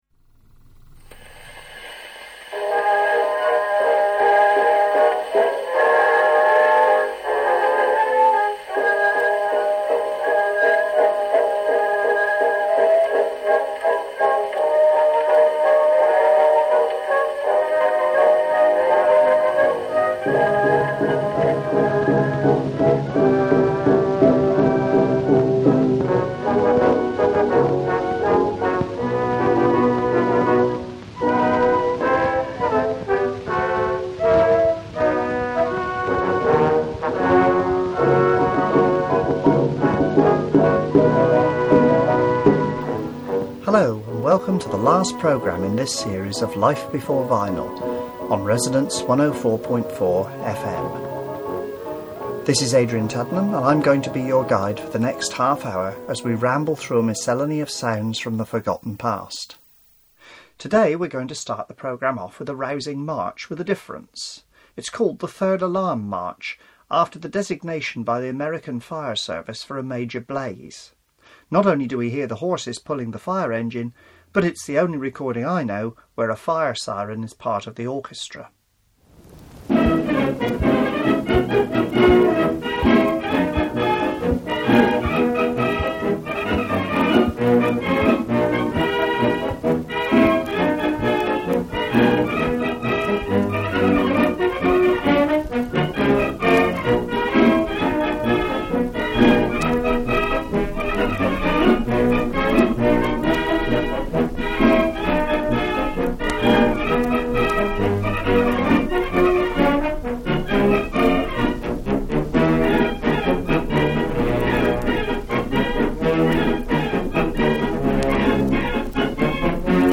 Sometimes the cutter was twisted when the record was made, which put the two sides of the groove out of step with each other.